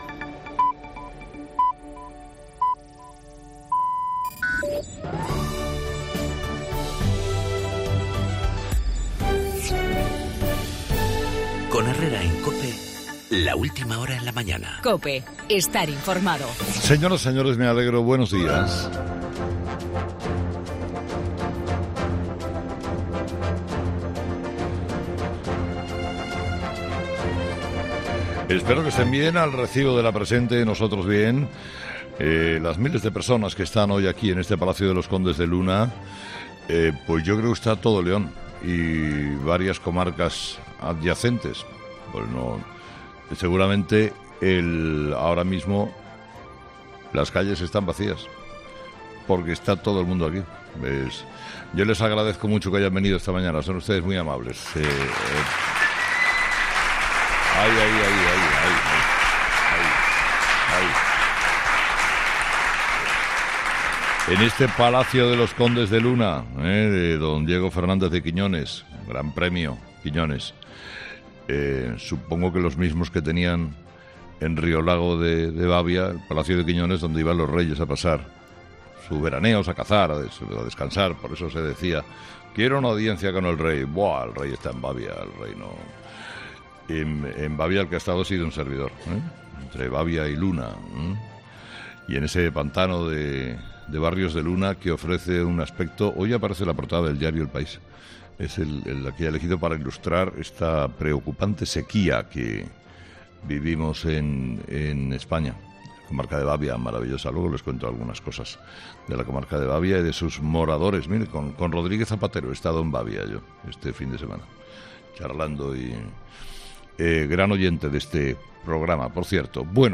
AUDIO: La bomba de hidrógeno lanzada por Kim Jong Un y el desafío soberanista, en una semana decisiva, en el monólogo de Carlos Herrera a las 8 de la...